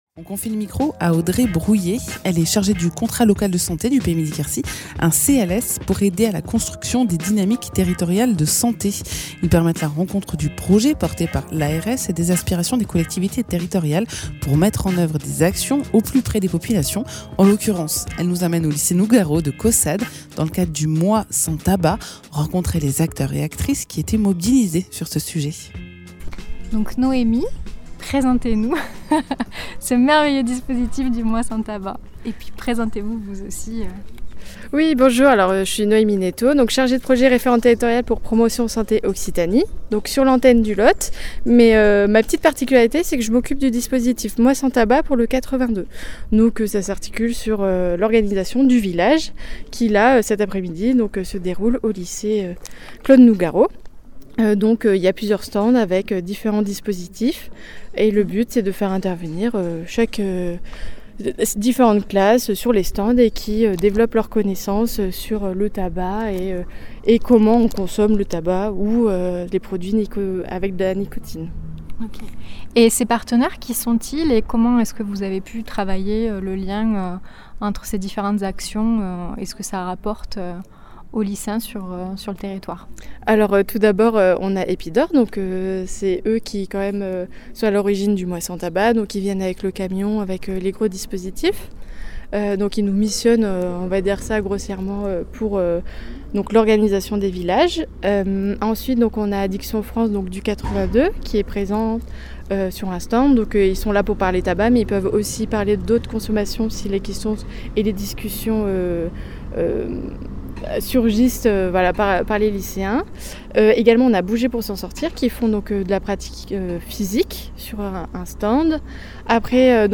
Reportage au lycée Nougaro de Caussade au village du mois sans tabac